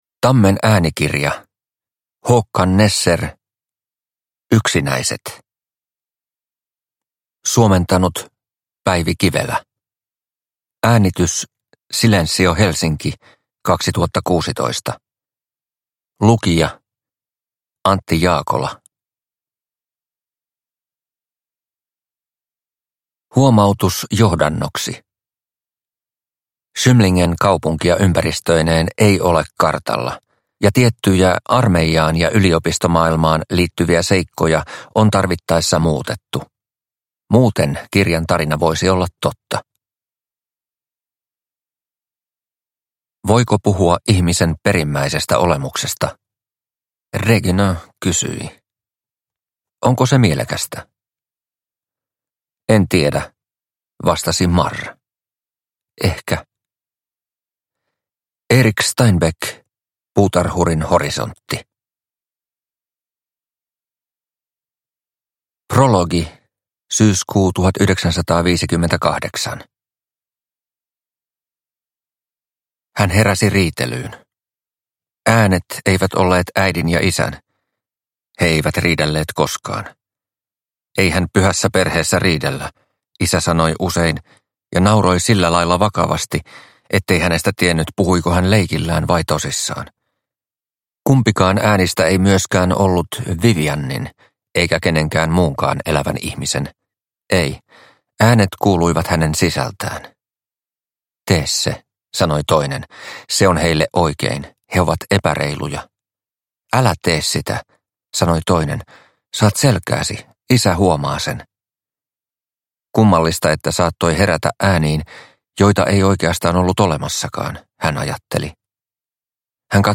Yksinäiset – Ljudbok